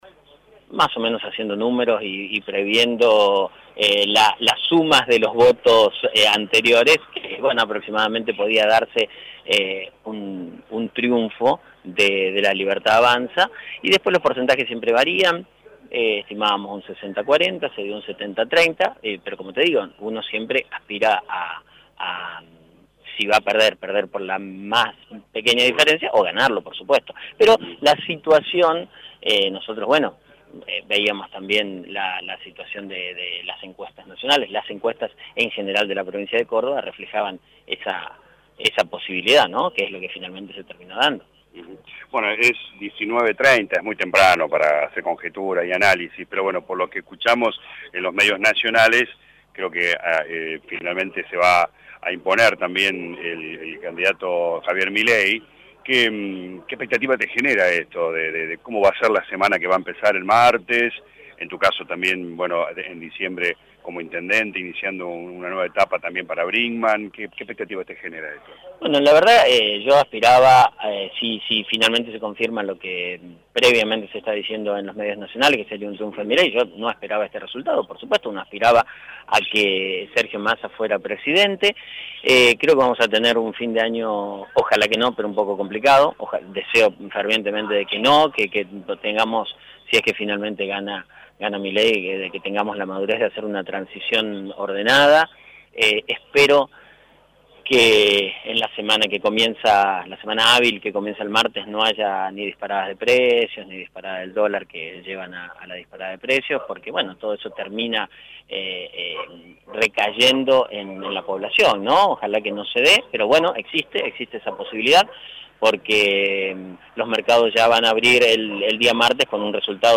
El intendente electo de la Brinkmann que asumirà el próximo diciembre, habló con LA RADIO 102.9 tras conocerse los resultados finales de los comicios en la ciudad: